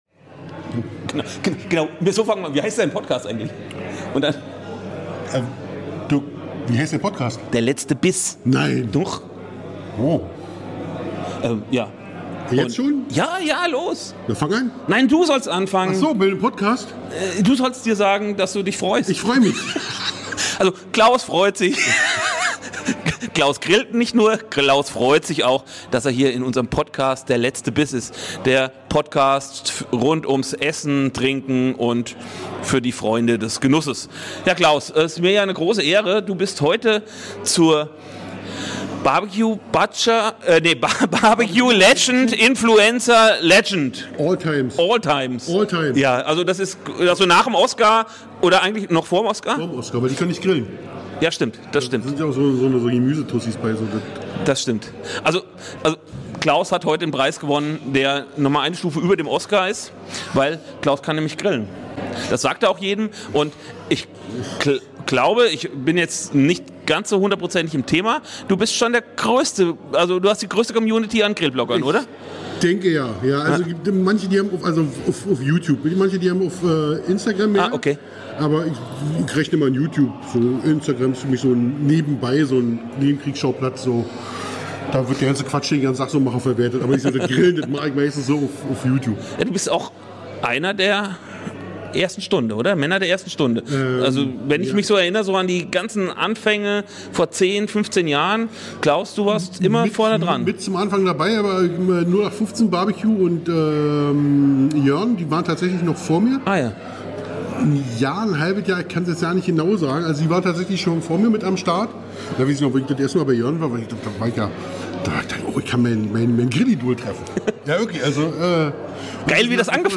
Ein offenes Gespräch über Grillen, Fleisch und Handwerk.
Du hörst persönliche Geschichten, ehrliche Meinungen und viele Einblicke aus Praxis und Erfahrung. Zwei Stimmen aus der BBQ-Szene.